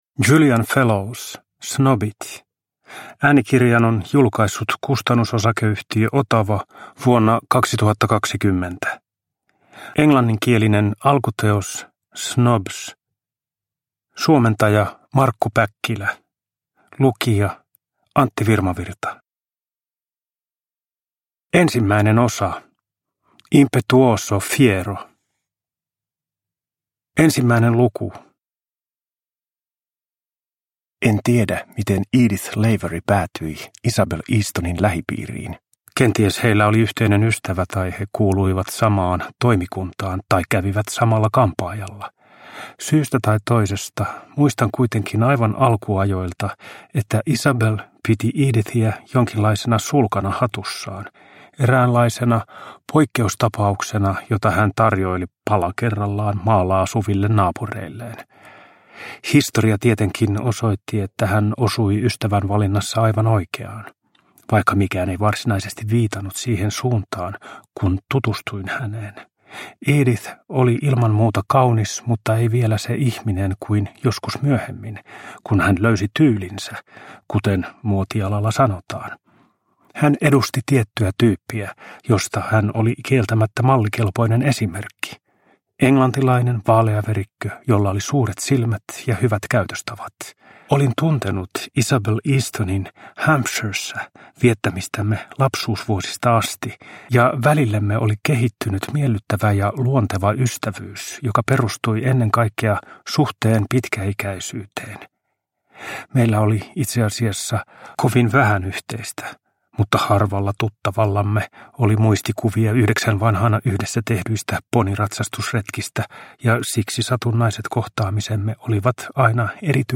Snobit – Ljudbok